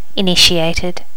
Additional sounds, some clean up but still need to do click removal on the majority.
initiated.wav